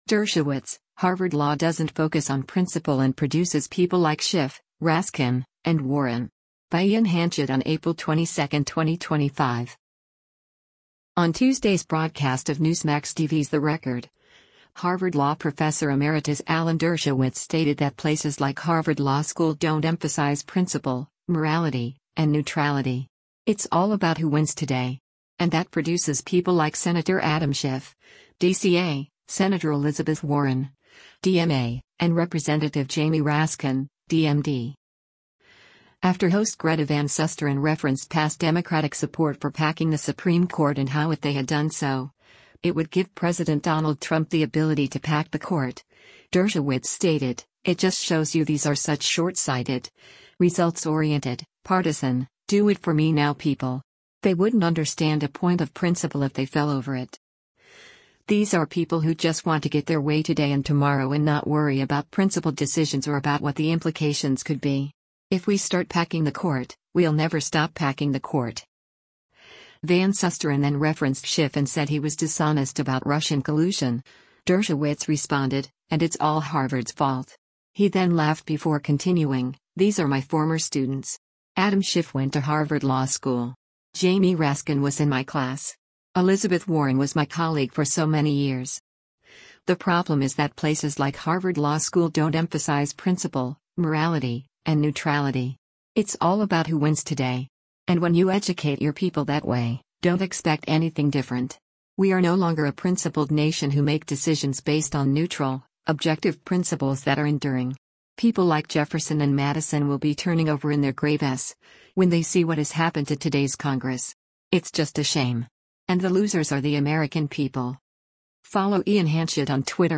On Tuesday’s broadcast of Newsmax TV’s “The Record,” Harvard Law Professor Emeritus Alan Dershowitz stated that “places like Harvard Law School don’t emphasize principle, morality, and neutrality. It’s all about who wins today.”
He then laughed before continuing, “These are my former students.